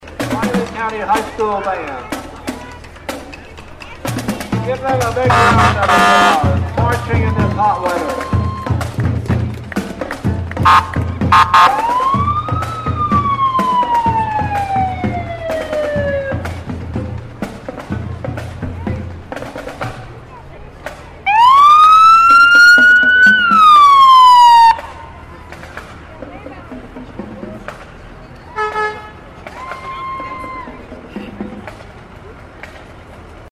It was more than half an hour long, and drew a large crowd.
The Riley County High School Marching Band flawlessly performed throughout the parade; with equipment from the Leonardville Fire Station coming behind them with lights and occasional sirens.